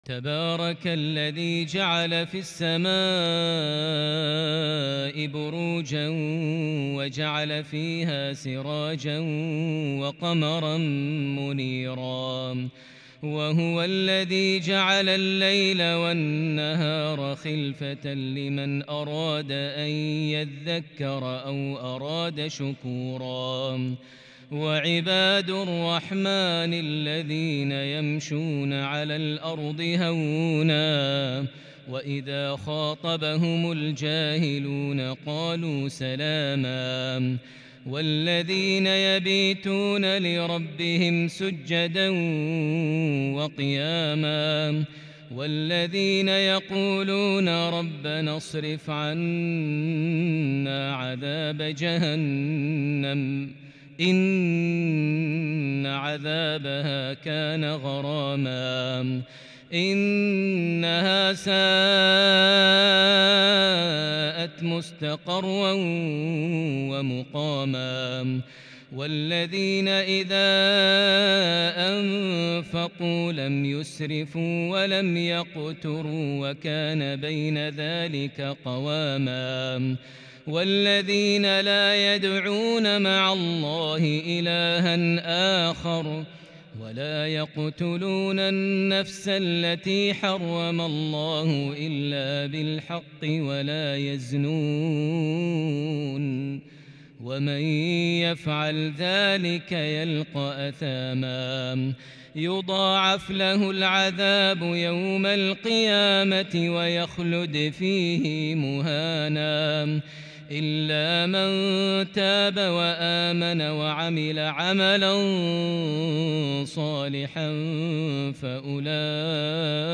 صلاة العشاء للشيخ ماهر المعيقلي 6 ذو الحجة 1442 هـ
تِلَاوَات الْحَرَمَيْن .